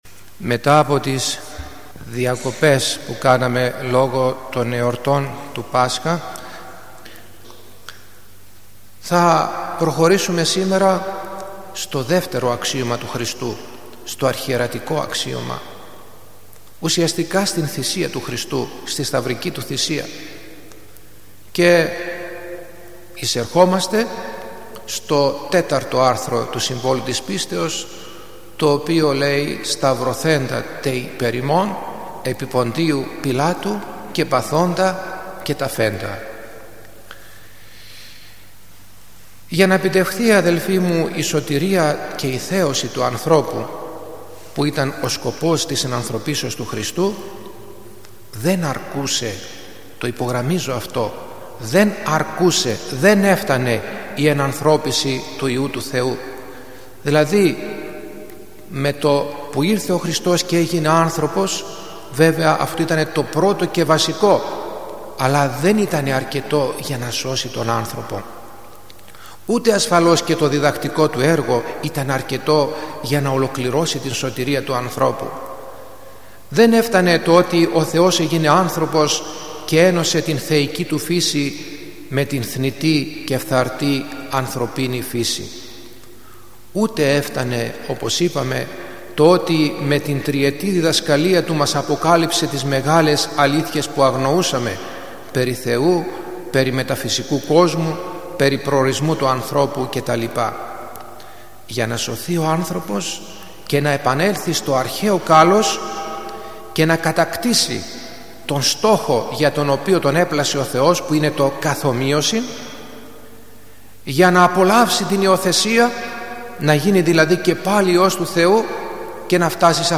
Μια ένθετη εκπομπή που μεταδίδονται ομιλίες